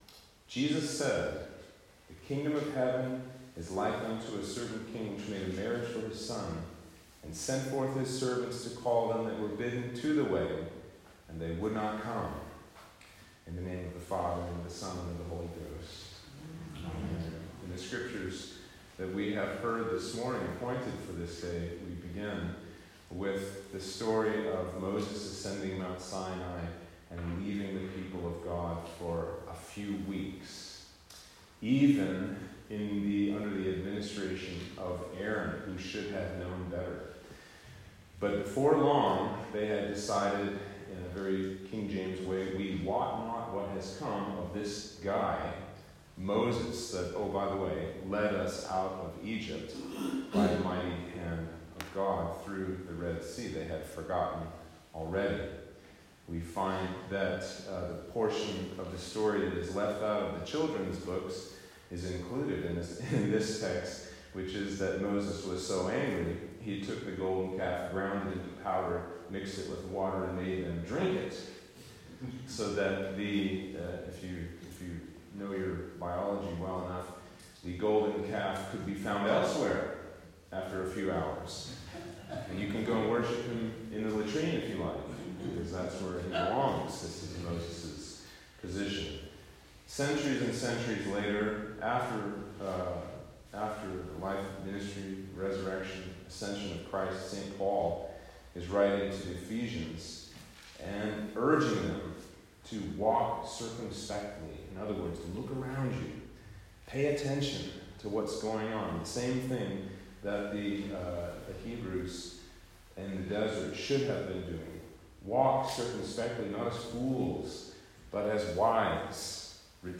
Sermon for Trinity 20